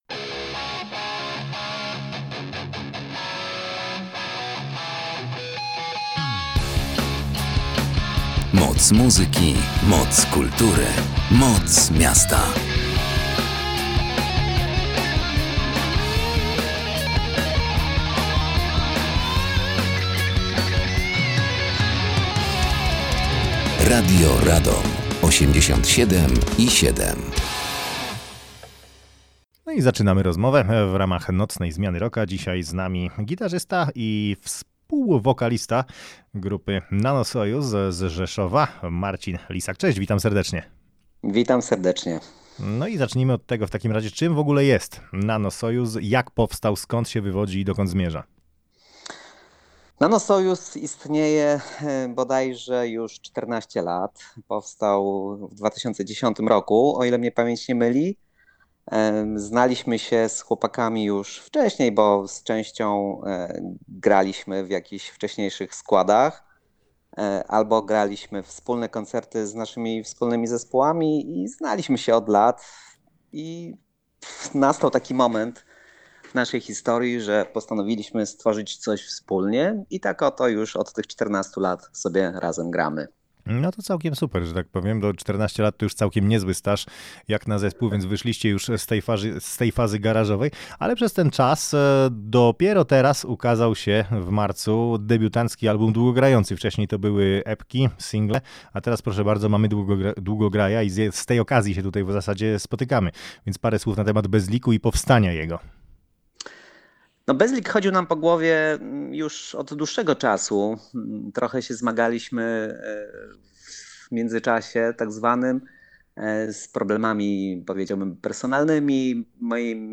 oto pełny zapis rozmowy